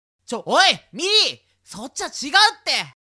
20代前半/男性
サンプルボイス